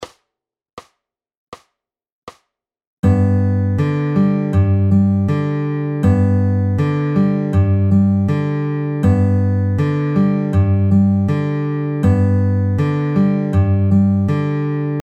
Exercise 6 is the same as Exercise 5 with a note removed!